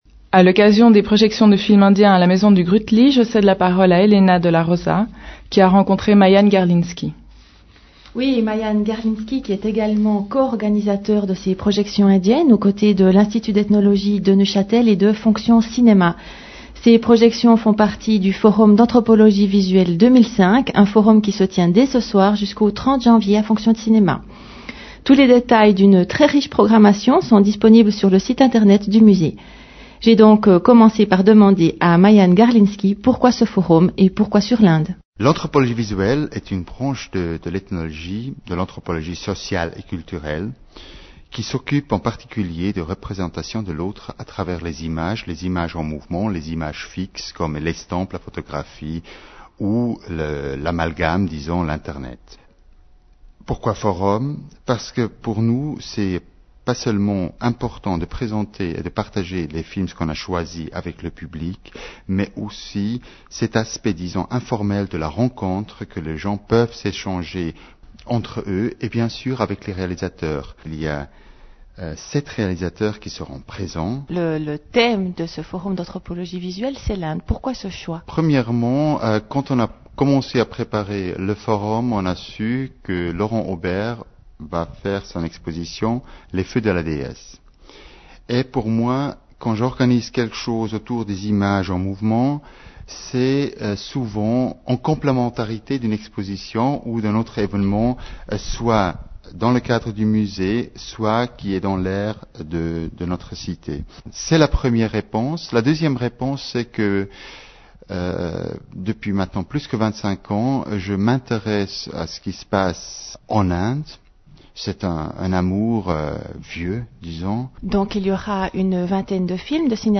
chronique cinéma